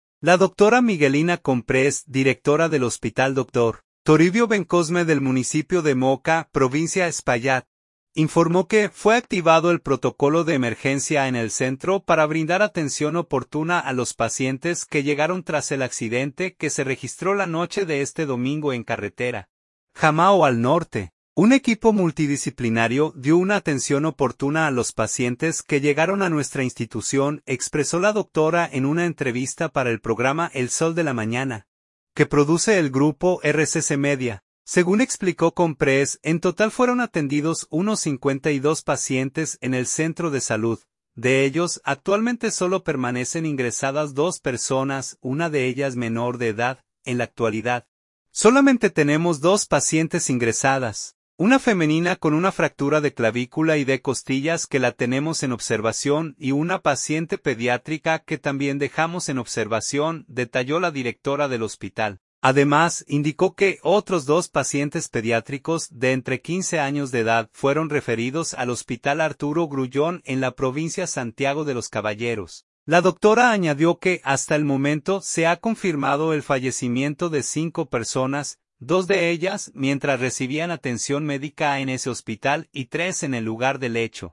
“Un equipo multidisciplinario dio una atención oportuna a los pacientes que llegaron a nuestra institución”, expresó la doctora en una entrevista para el programa El Sol de la Mañana, que produce el Grupo RCC Media.